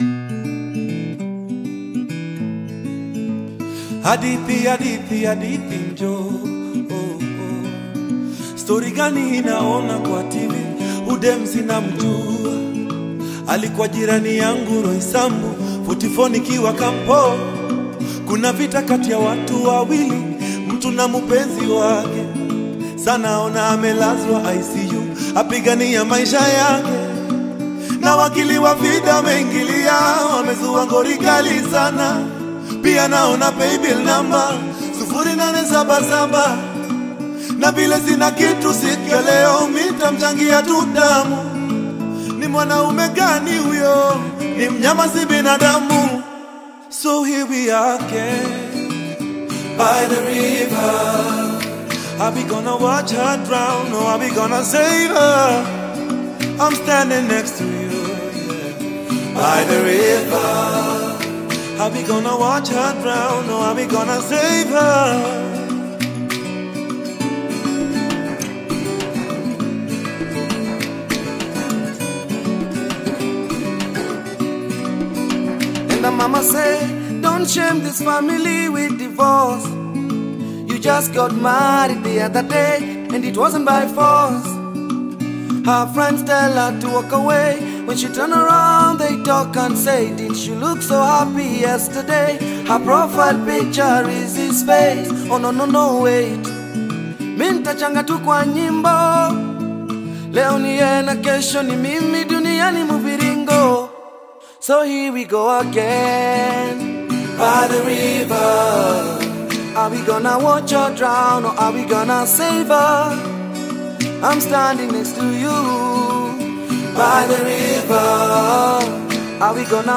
signature harmonies